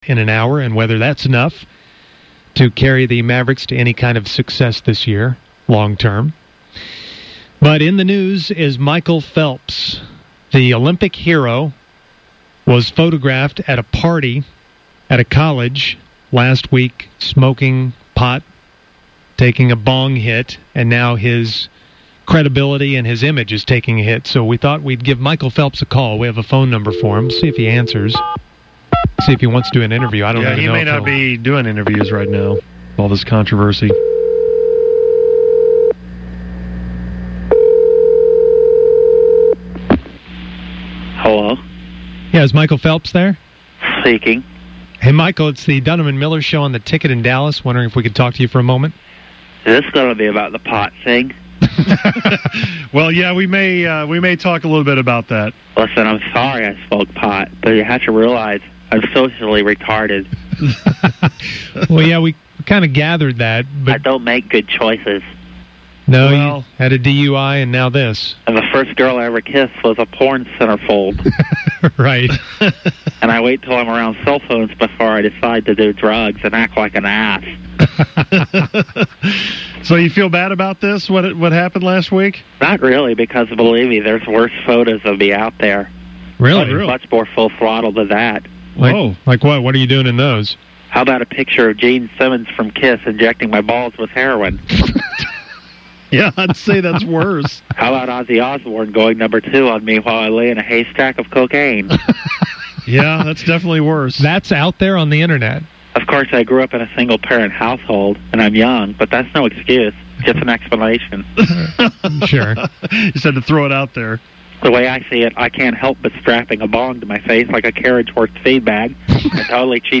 The Musers were able to call Phelps to talk to him about his picture and his pot use. He sounded like someone was holding his nose while he talked, but he was very defensive on the issue.
fake-michael-phelps.mp3